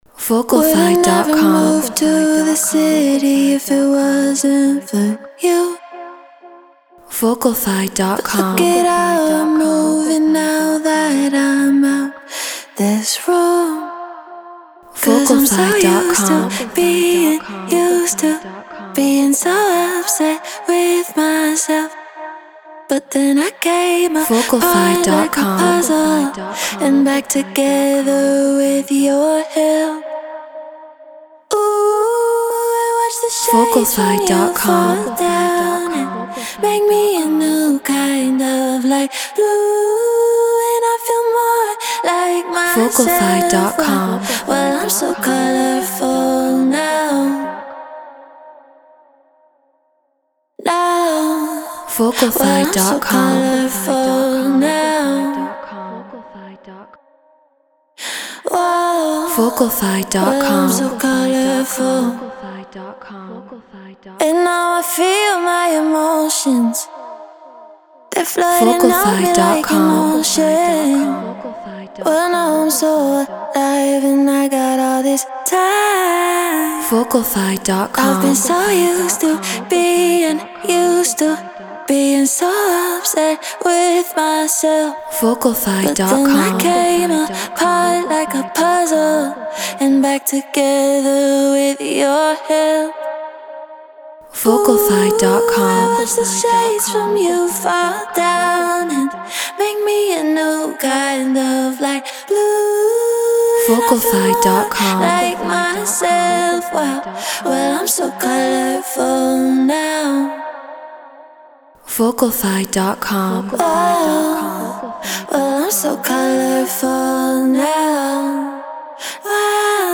Get Royalty Free Vocals.
Non-Exclusive Vocal.